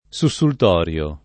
sussultorio [ S u SS ult 0 r L o ]